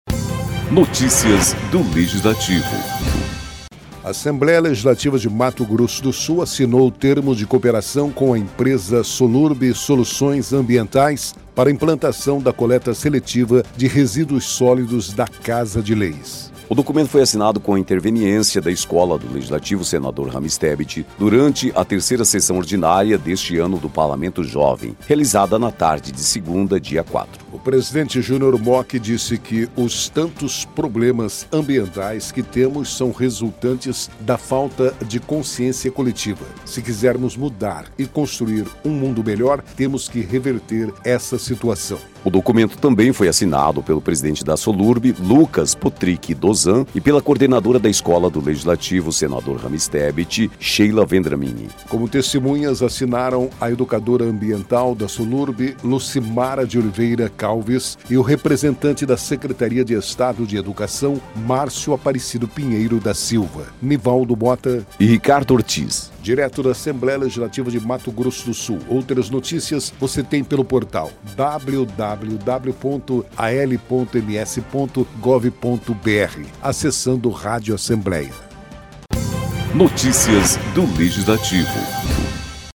O documento foi assinado, com a interveniência da Escola do Legislativo Senador Ramez Tebet, durante a terceira sessão ordinária deste ano do Parlamento Jovem, realizada na tarde desta segunda-feira (dia 4) no Plenário Deputado Júlio Maia.